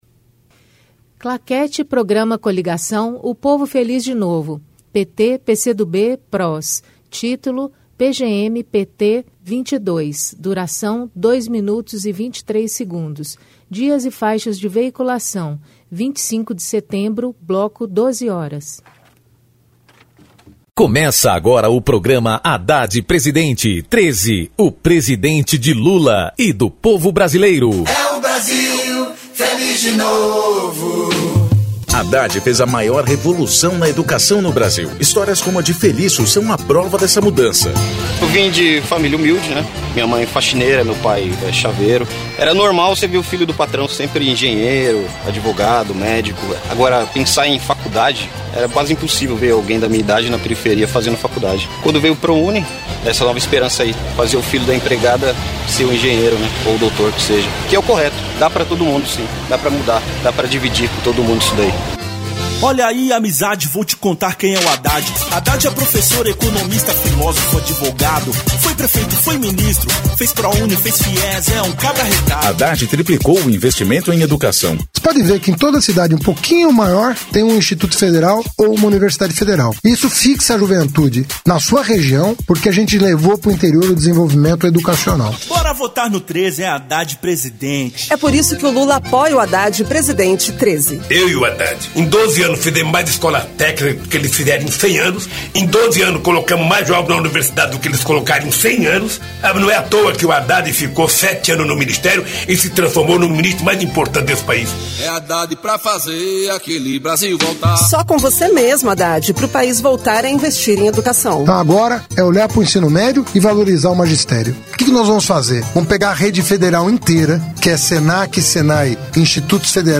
TítuloPrograma de rádio da campanha de 2018 (edição 22)
Descrição Programa de rádio da campanha de 2018 (edição 22) - 1° turno